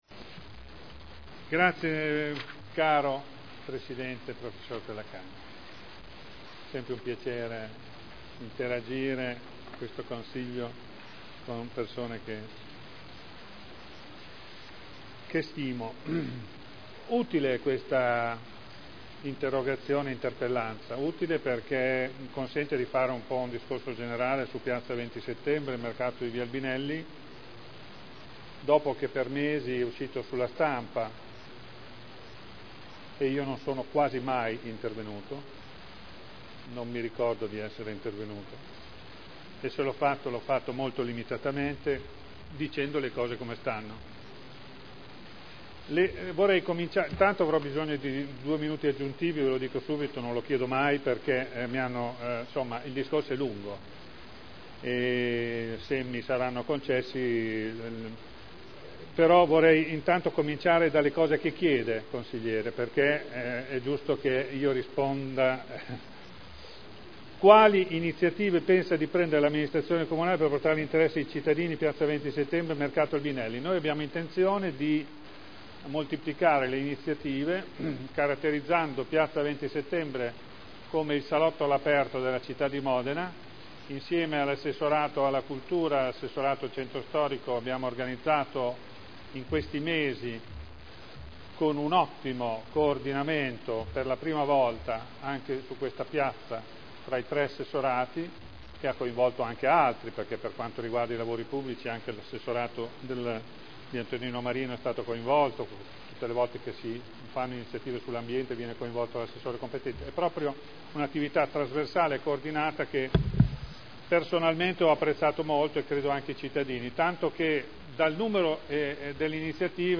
Graziano Pini — Sito Audio Consiglio Comunale